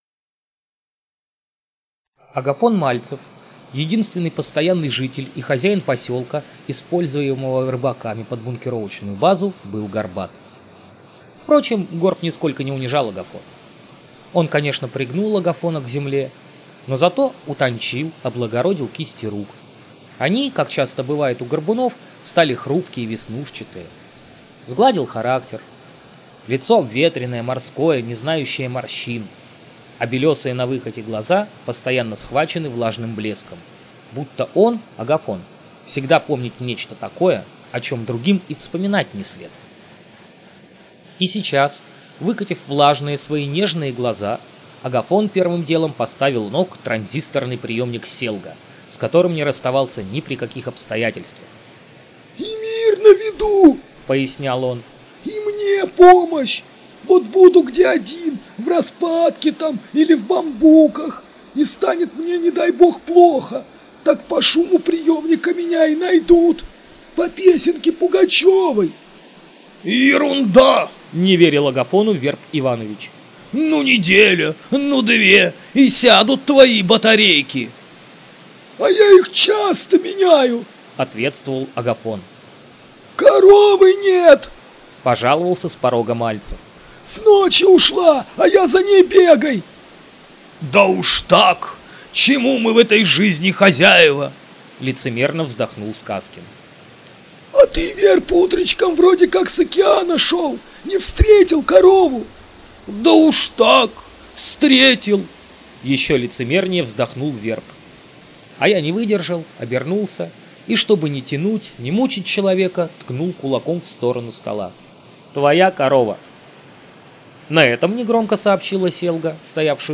Демо-запись №1 Скачать